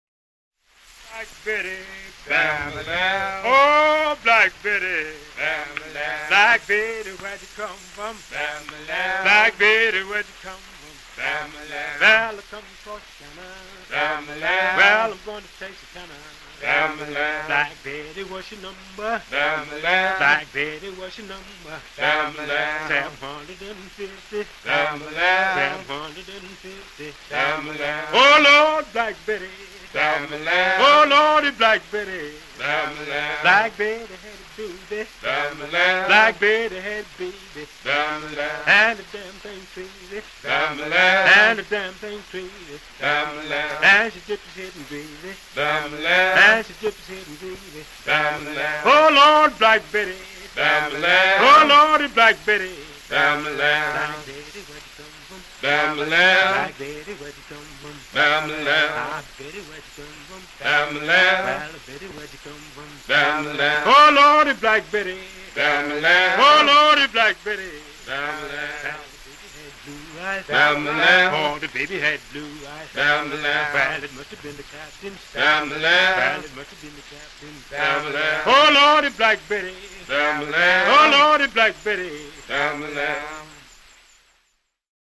idem: Black Betty (1933, refrain vocalizzato)
canto di lavoro | | salmo (liturgia moderna) |